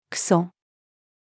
糞-female.mp3